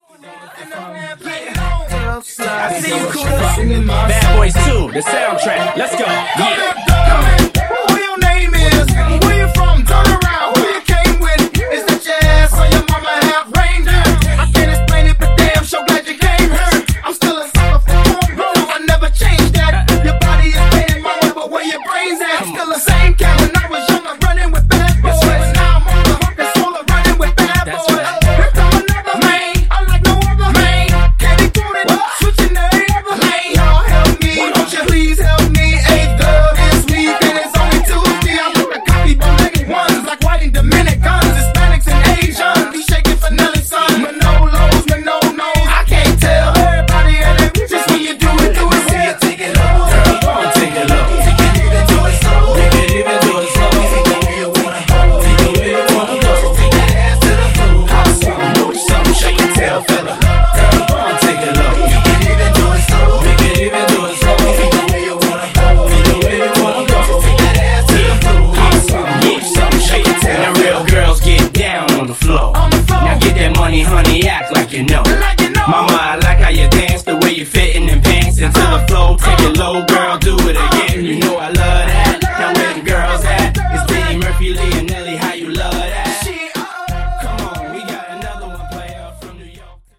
Genre: HIPHOP